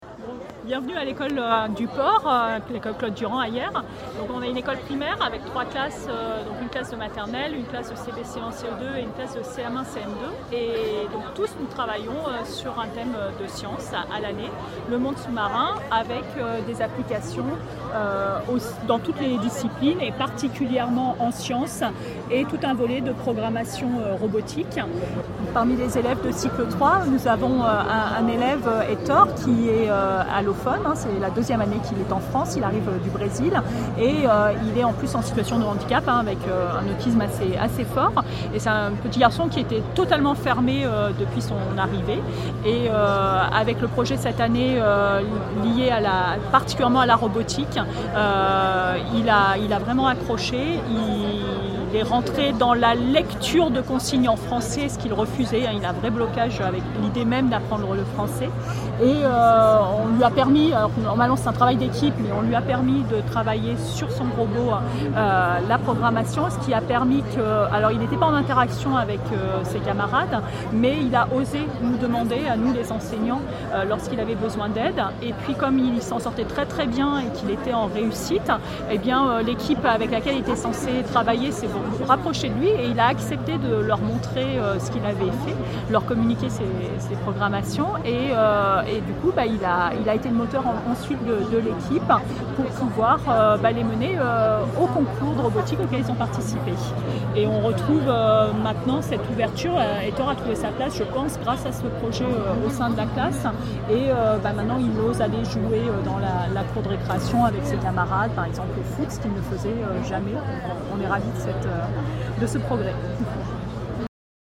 En direct de la Journée académique pédagogie et innovation « Japi‘ » portée par la CARDIE et l’EAFC, nous avons recueilli des témoignages d’enseignantes sur l’appropriation par les élèves allophones des projets proposés en classe.